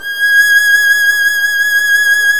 Index of /90_sSampleCDs/Roland - String Master Series/STR_Violin 1 vb/STR_Vln1 % + dyn
STR VLN MT0V.wav